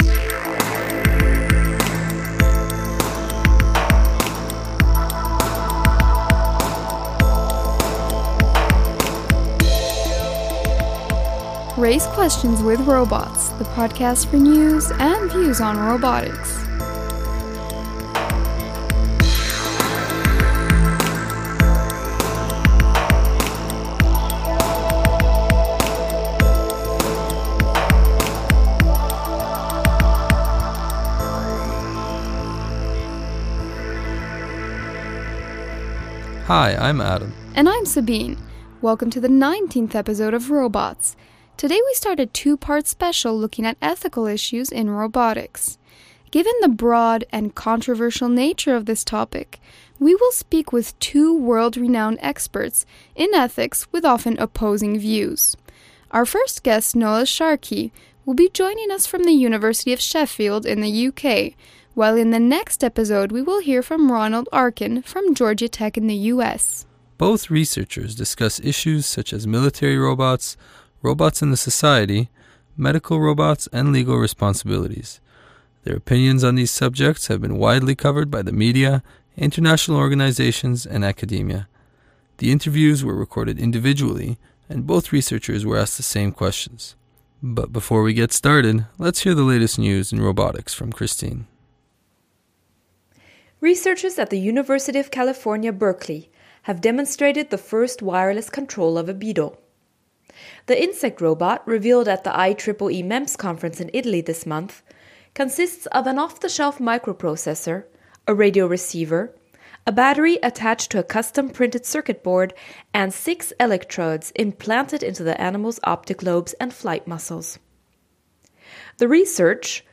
The interviews were recorded individually and both researchers were asked the same questions.